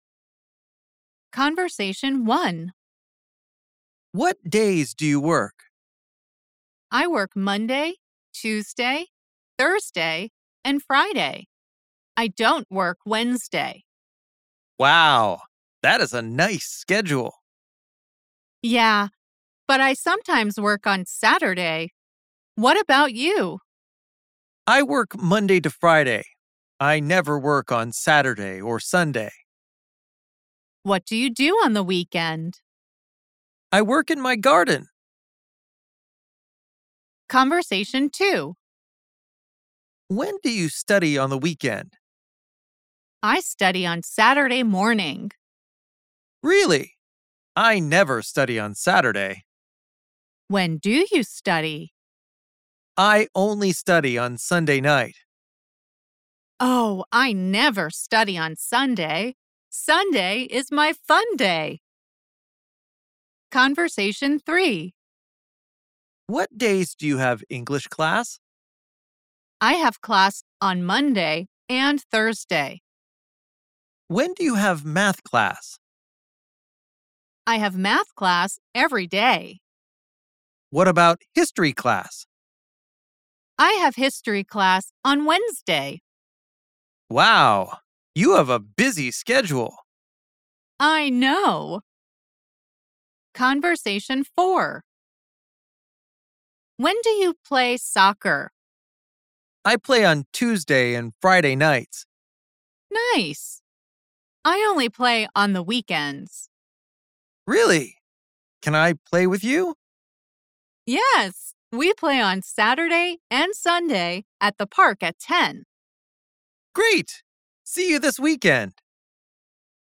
Where do the woman and man play soccer on the weekends?